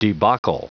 Prononciation du mot debacle en anglais (fichier audio)